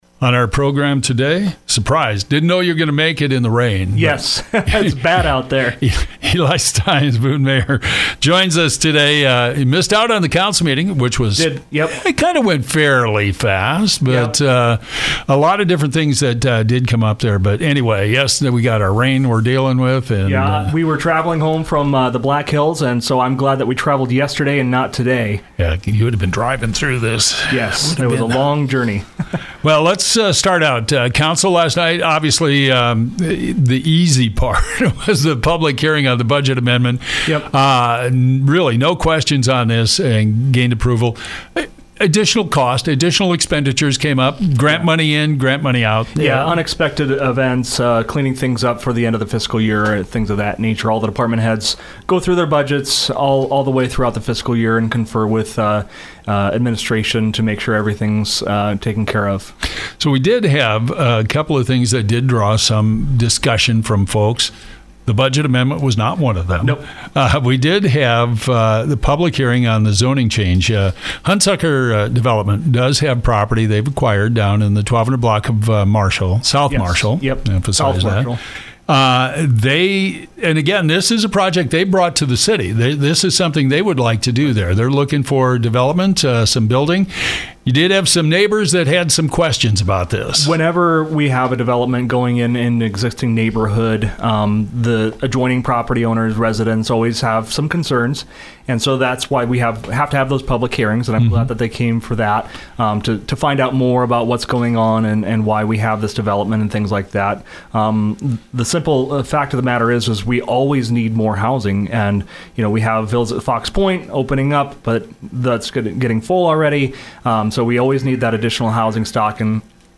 Eli Stines, Boone Mayor, talks about the Boone City Council meeting. Stines noted the budget amendment for the current fiscal year was passed with no questions. There were questions from neighbors to a proposed development in the 1200 Block of South Marshall Street.